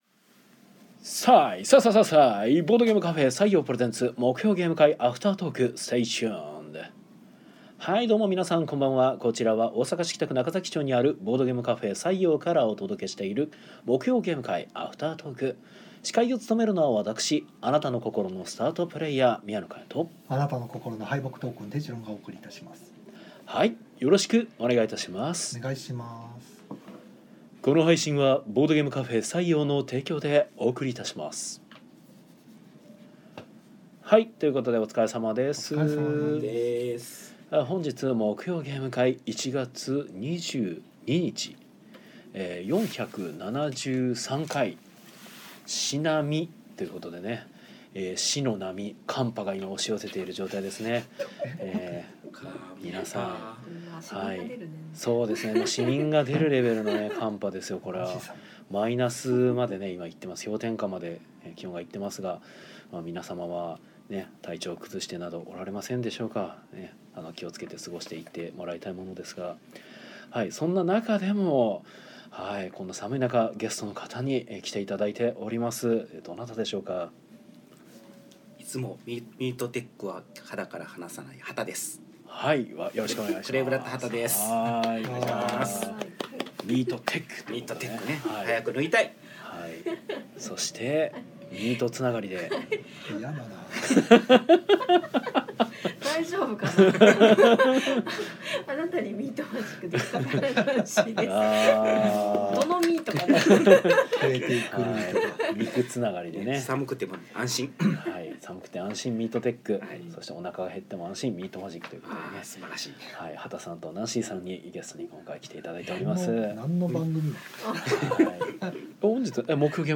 ゲーム会の話や、近況などをダラダラと生配信で垂れ流したものを鮮度そのままノーカットでパッケージング！（podcast化）